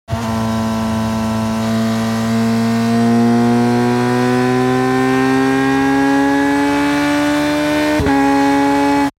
دانلود آهنگ موتور 6 از افکت صوتی حمل و نقل
دانلود صدای موتور 6 از ساعد نیوز با لینک مستقیم و کیفیت بالا
جلوه های صوتی
برچسب: دانلود آهنگ های افکت صوتی حمل و نقل دانلود آلبوم صدای موتورسیکلت از افکت صوتی حمل و نقل